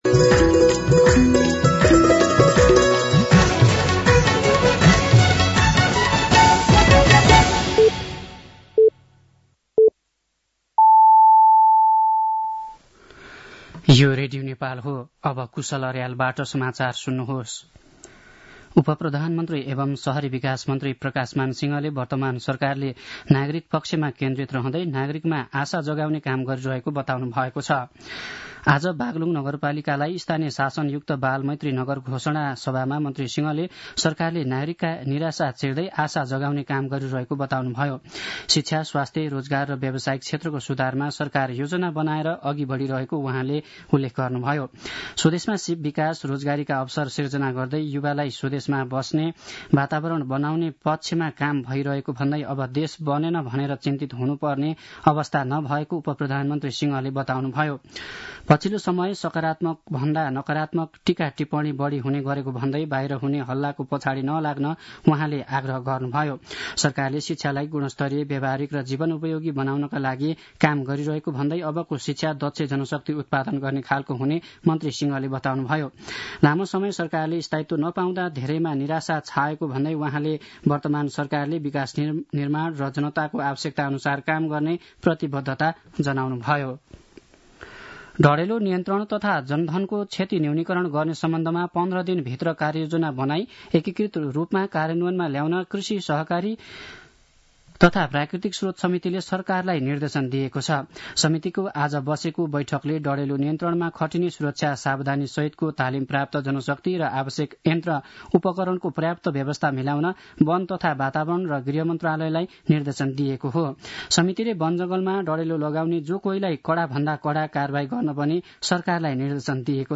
साँझ ५ बजेको नेपाली समाचार : १० माघ , २०८१